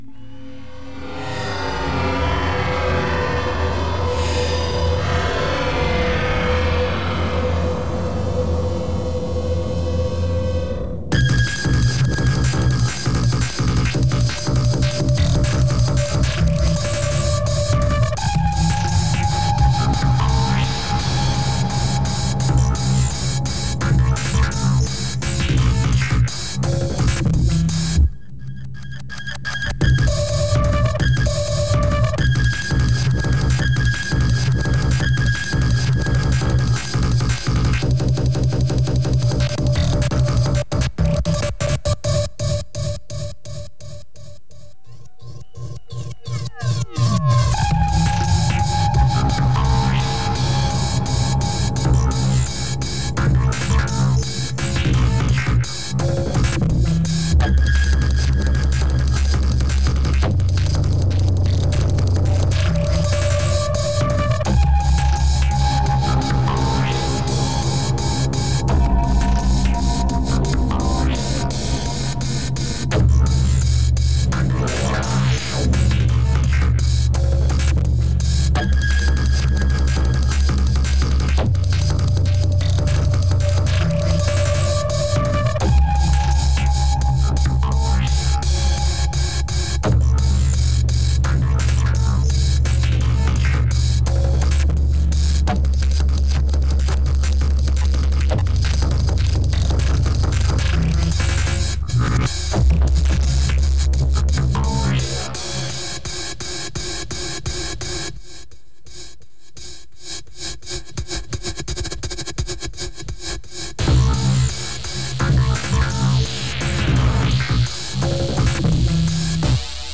Liveset 1.
event Roskilde Festival